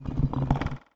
Rattle3.ogg